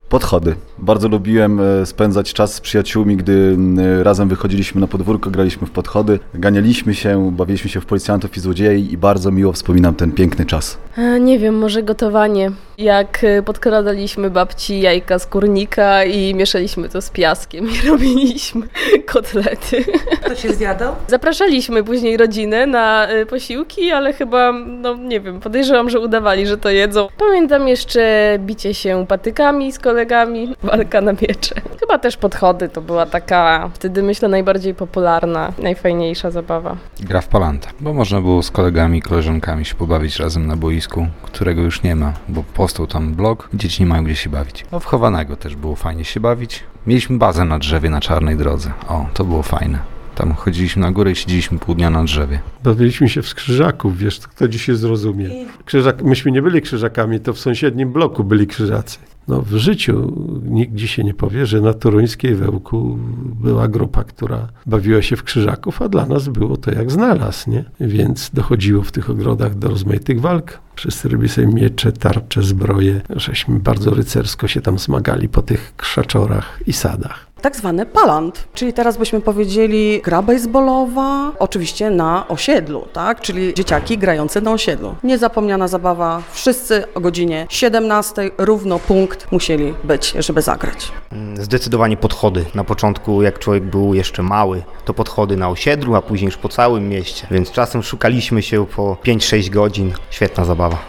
O powrót do dziecięcych zabaw poprosiliśmy też naszych redakcyjnych kolegów i przyjaciół radia.